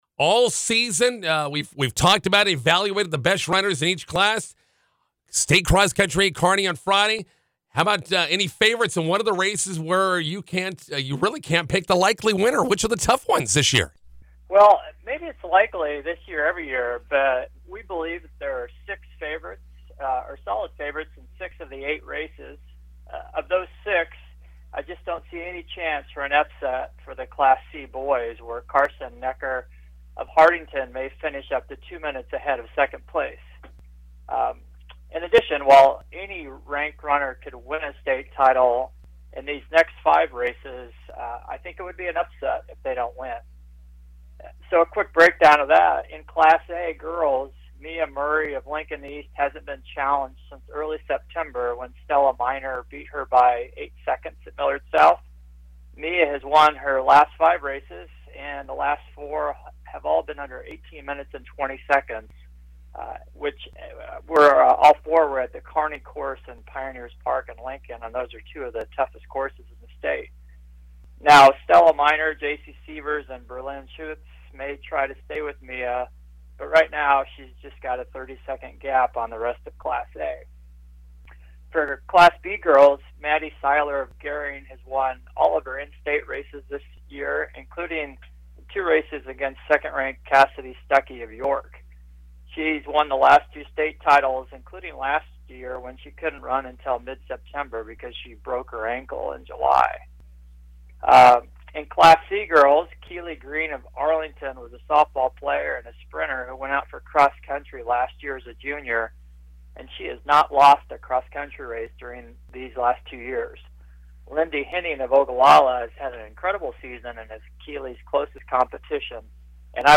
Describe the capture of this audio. If you’d rather listen to the radio interview, you can click here, but we’re probably better writers than orators. We’ll start off with our pre-State favorites.